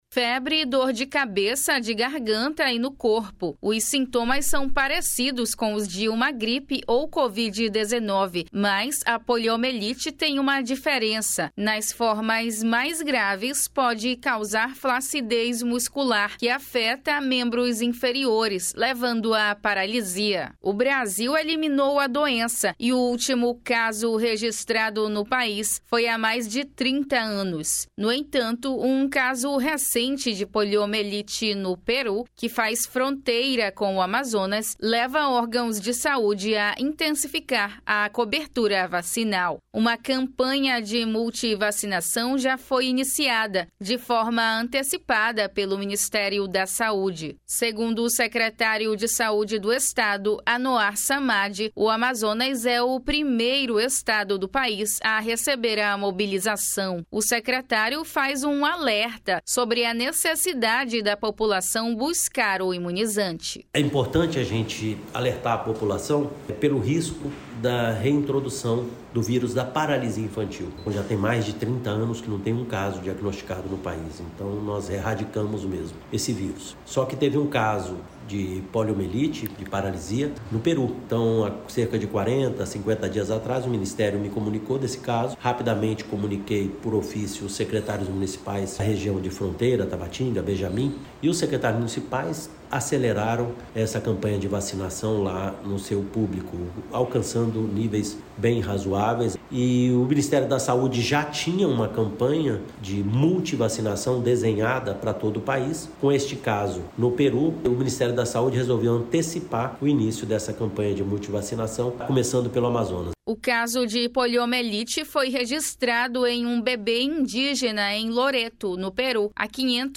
Reportagem
O secretário faz um alerta sobre a necessidade de a população buscar o imunizante.
O secretário explica como funciona a estratégia durante a mobilização contra a poliomelite e outras doenças.